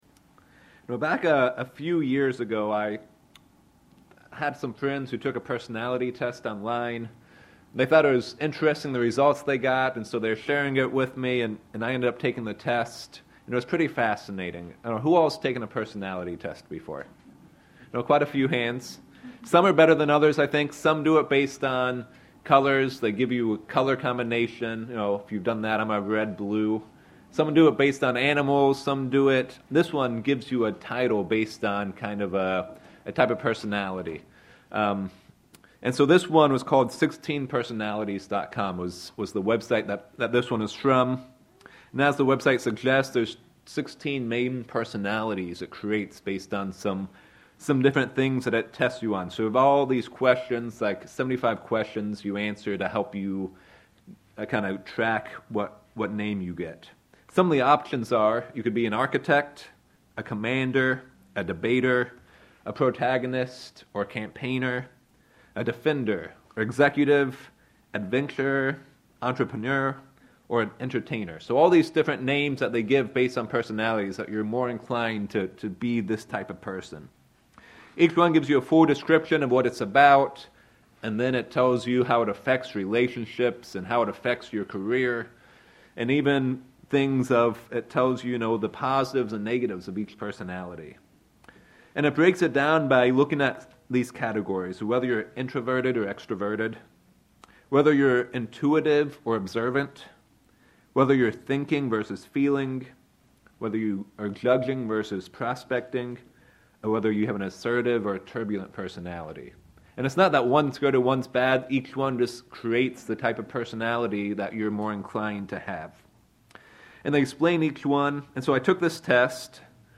Sermon
Given in Sacramento, CA